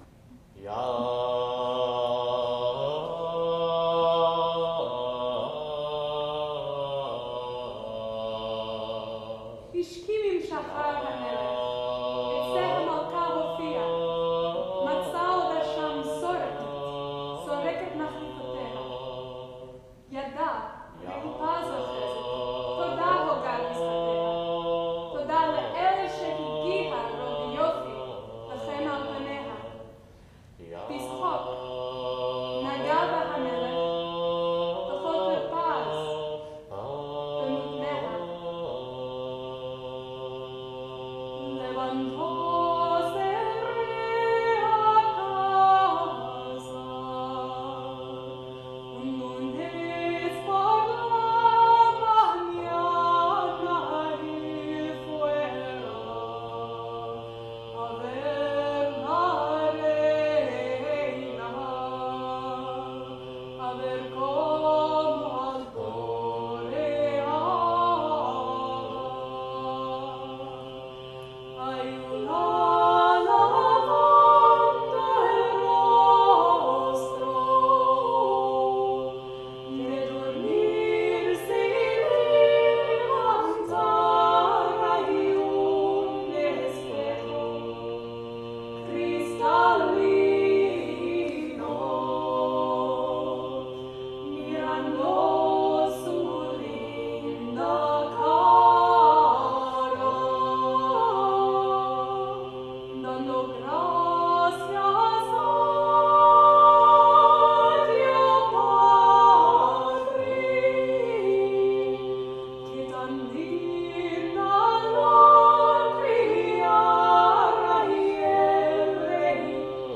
Landariko, traditional Ladino lullaby